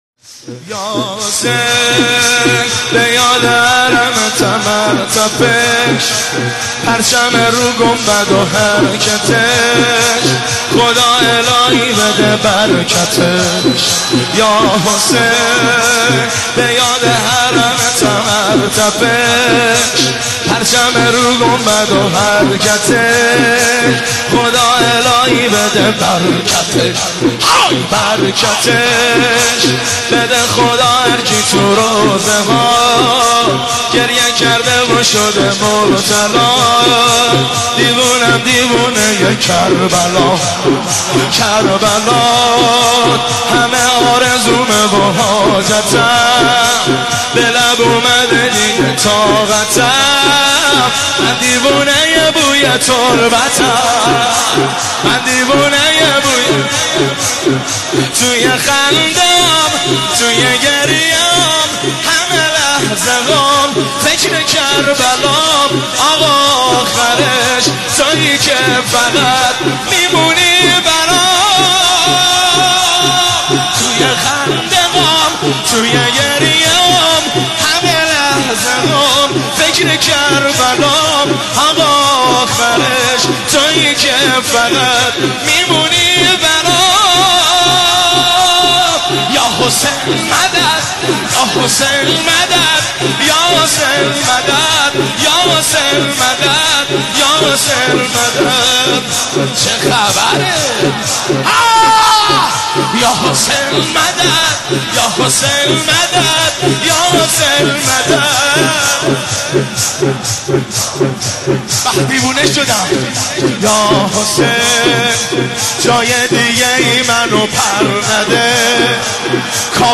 مداحی یا حسین به یاد حرمتم(شور)
رمضان 1393
هیئت بین الحرمین